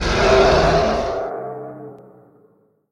mgroan13.mp3